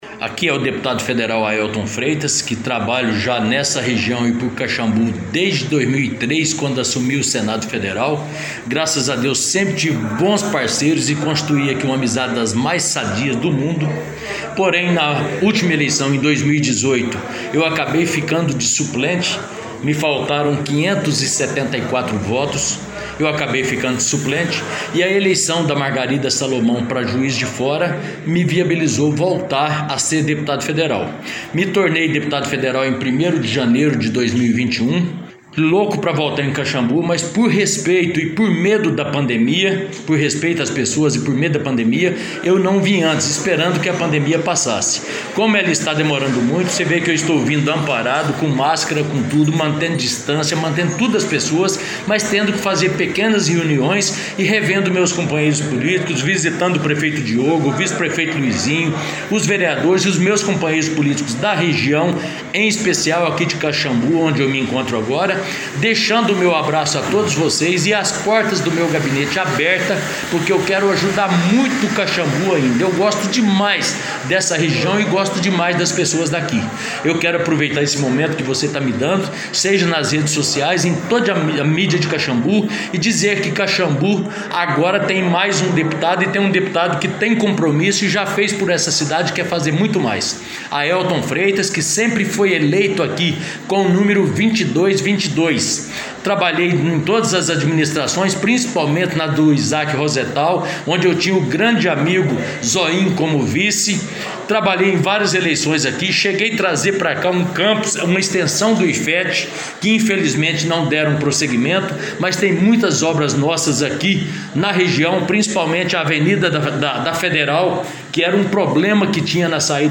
Ouça abaixo uma entrevista EXCLUSIVA com o Deputado Federal Aelton Freitas